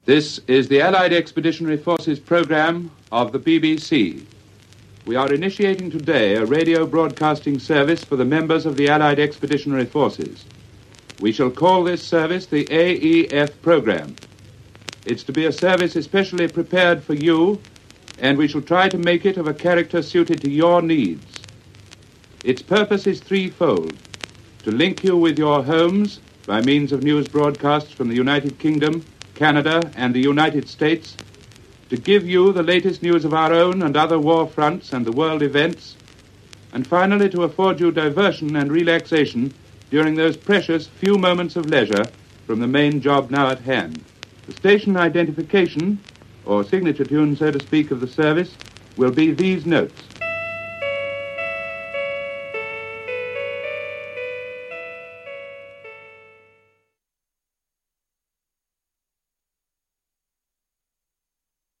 On June 7th 1944, on 1050 kHz AM, (later 583), the Allied Expeditionary Forces Programme began, broadcasting from Broadcasting House. Famously, the childish yet eerie tune ‘Oranges and Lemons’ was adopted as its call-sign, played on a nova-chord. There was an introductory announcement by Franklin Engelmann, who was later to be the first host of ‘Pick of the Pops’, followed by a prayer and a news bulletin.